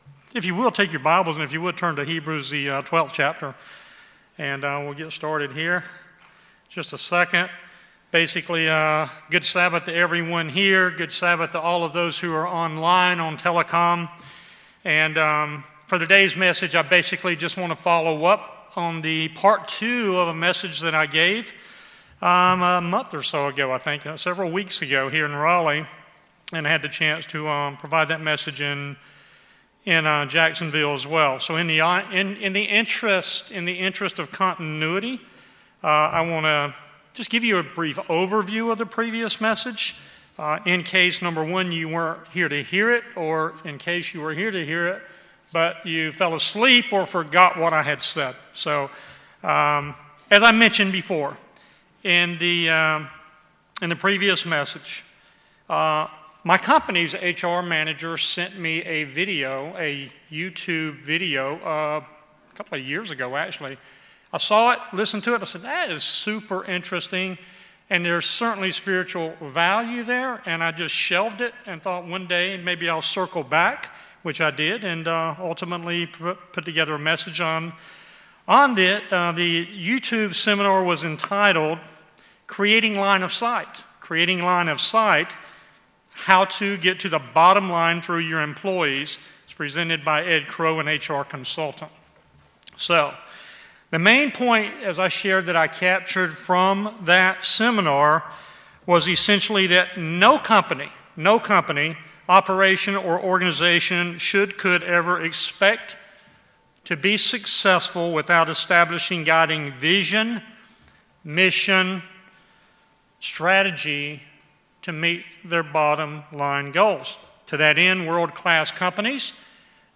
Sermons
Given in Raleigh, NC Jacksonville, NC